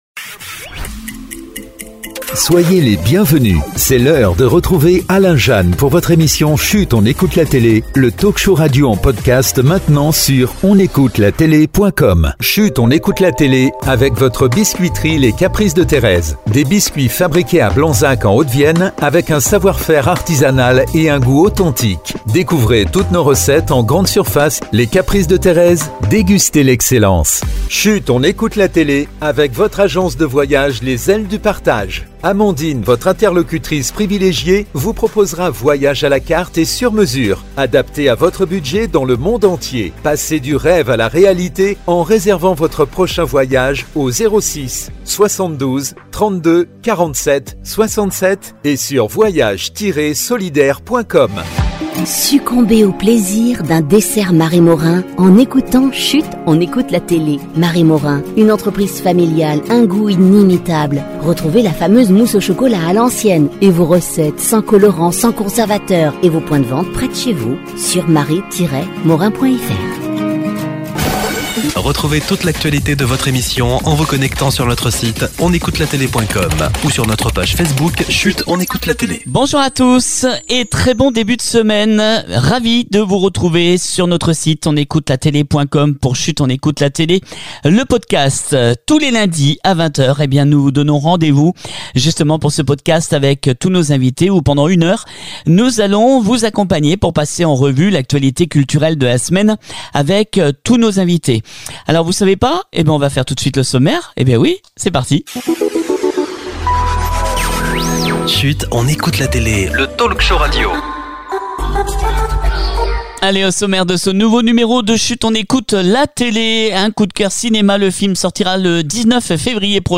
On se retrouve ce lundi 10 Février 2025 pour un nouveau rendez vous de Chut on écoute la télé avec de nombreux invités, on parle de